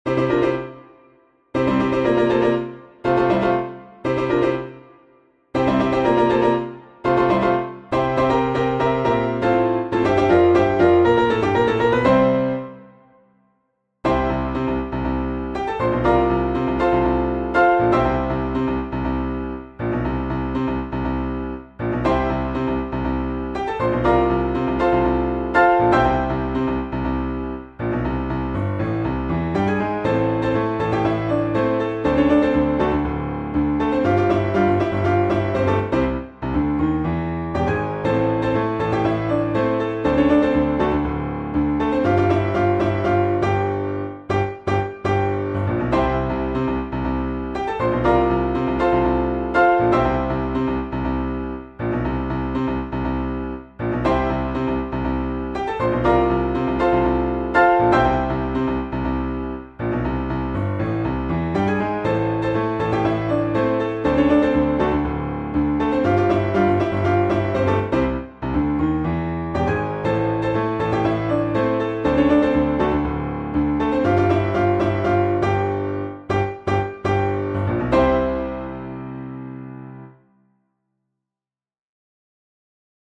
A simplified piano duet